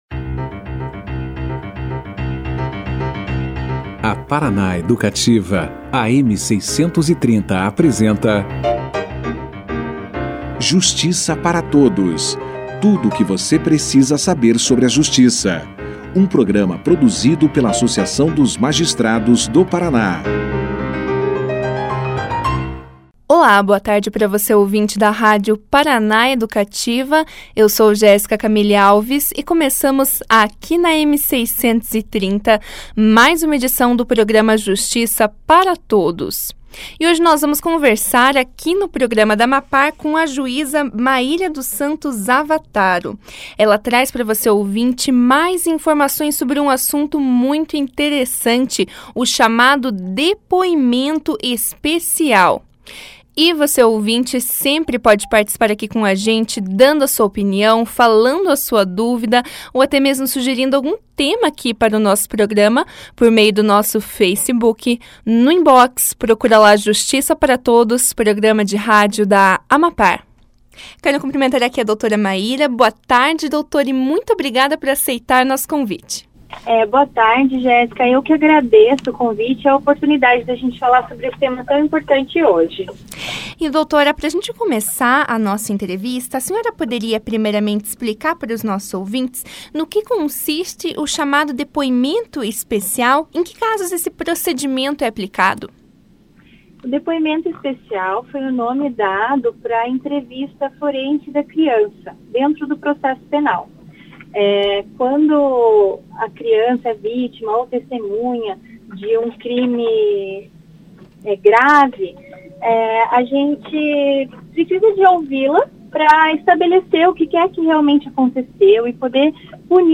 A juíza Mayra dos Santos Zavattaro conversou com o Justiça para Todos, nesta quarta-feira (21), sobre o chamado depoimento especial, metodologia diferenciada para entrevistar crianças e adolescentes vítimas ou testemunhas de um crime.